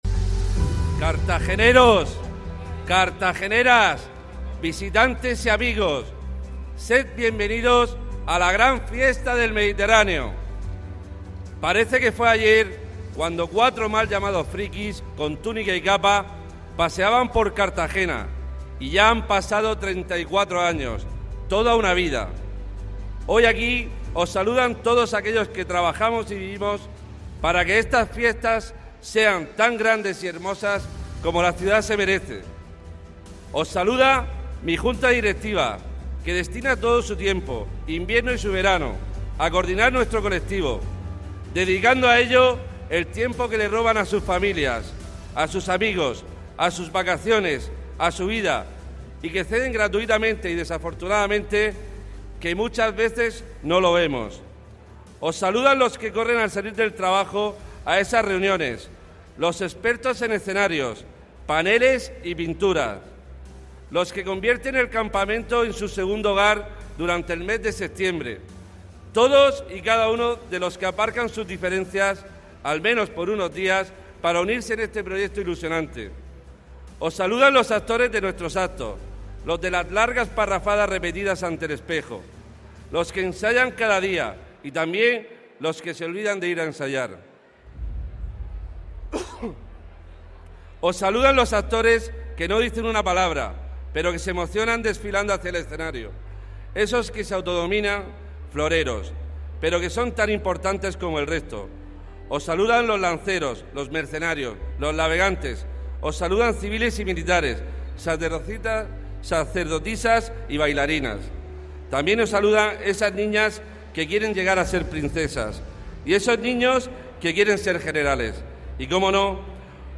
Audio: Preg�n de Carthagineses y Romanos por Tom�s Roncero y Crist�bal Soria. (MP3 - 8,03 MB)